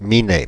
Ääntäminen
France (Paris): IPA: /mi.nɛ/